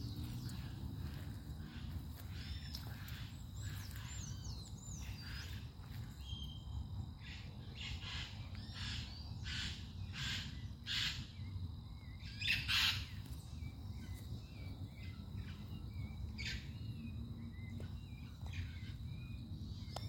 Maitaca-verde (Pionus maximiliani)
Nome em Inglês: Scaly-headed Parrot
Localidade ou área protegida: San Miguel, capital
Condição: Selvagem
Certeza: Gravado Vocal
loro-maitaca.mp3